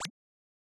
generic-hover-softer.wav